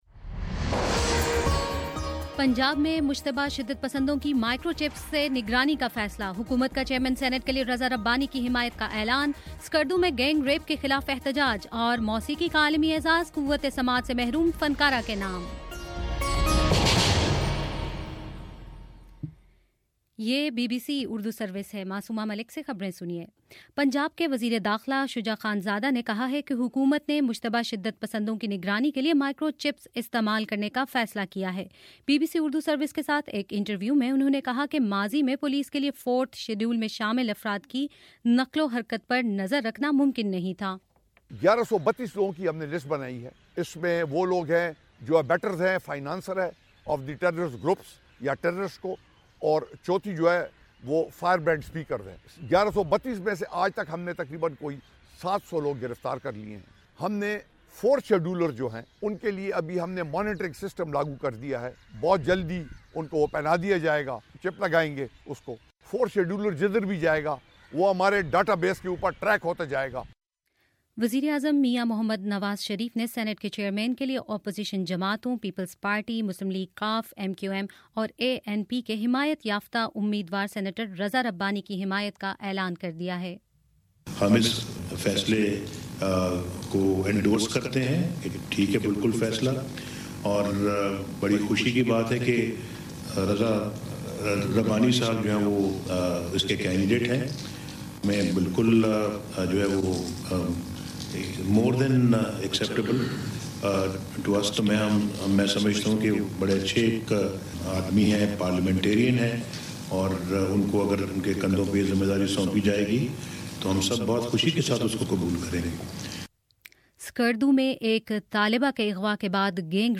مارچ 10: شام چھ بجے کا نیوز بُلیٹن